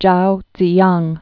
(jou dzē-yäng) or Chao Tzu-yang (jou dz-) 1919-2005.